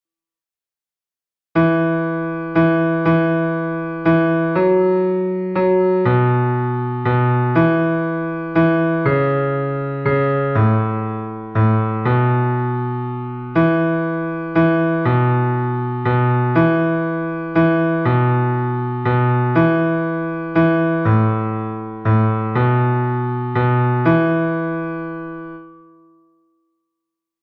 This page contains recordings of the notes for the bass voice parts for the song that will be presented during the Christmas Sunday service (December 21, 2025).
Note that blank measures for the parts are not skipped; it follows the music as written so if you hear silence that's because there's nothing written for your part in that portion of the recording (i.e., your device isn't broken and your ears still work).